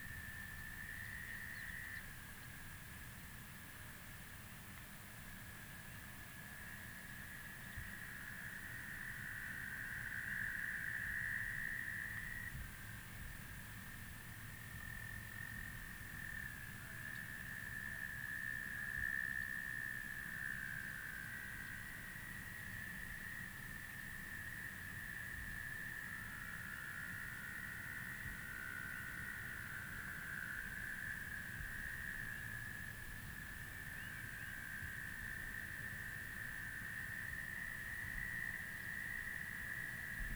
swamp-01.wav